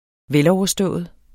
Udtale [ ˈvεlɒwʌˌsdɔˀʌð ]